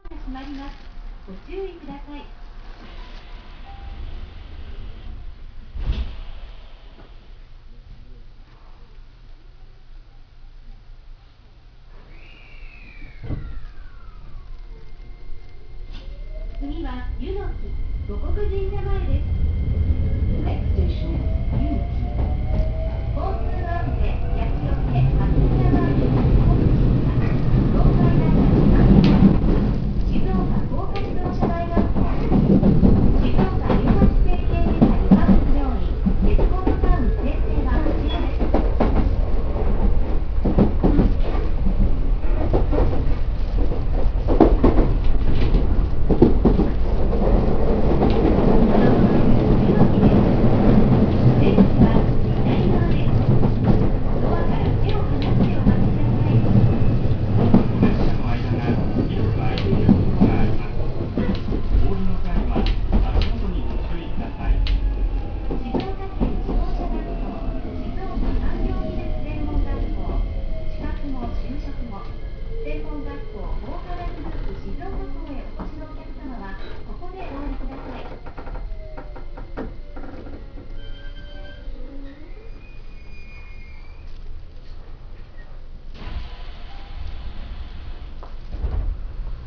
〜車両の音〜
A3000形走行音
【静岡清水線】春日町〜柚木（1分29秒：487KB）
ドアそのものはJR東日本のような見た目ですが、ドアチャイムは東急タイプ。走行音はよくある東洋IGBTなので、これと言って面白みはありません。車内放送は何故か男女２名の担当となっています。